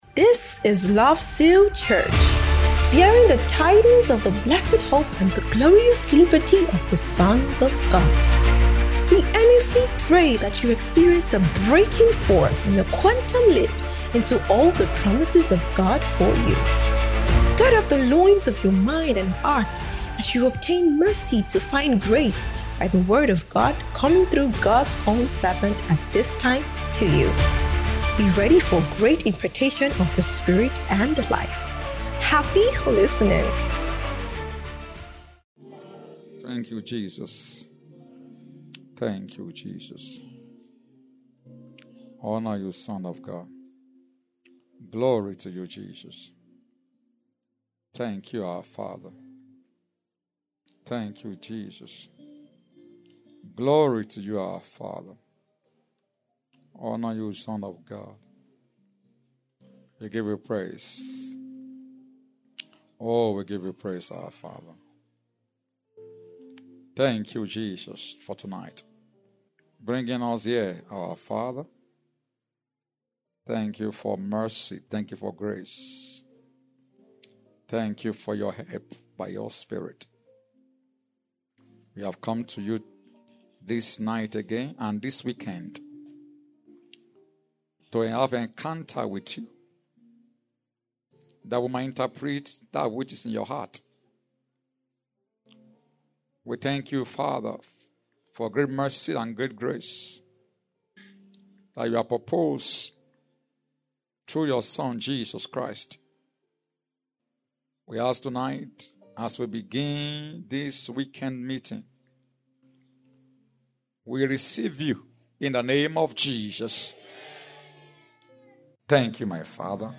Family & ForeverOne Summit 2025